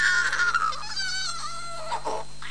choke3.mp3